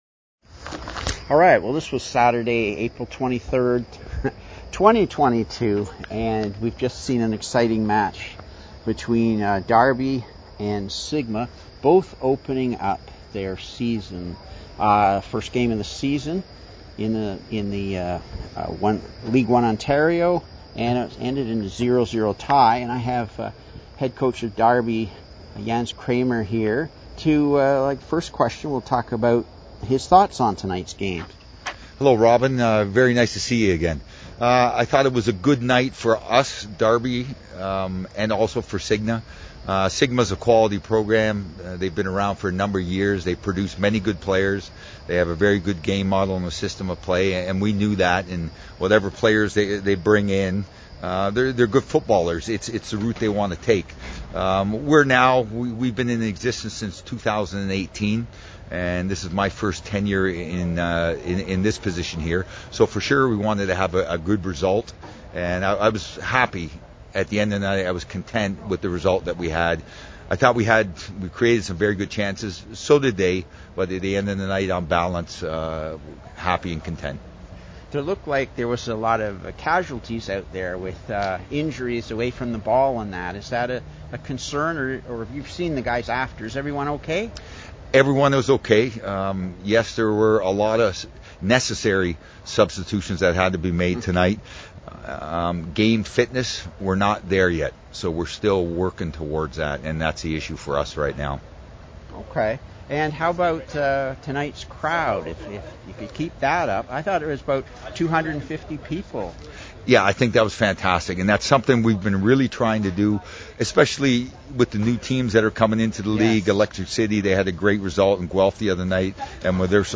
Post match interview with each head coach: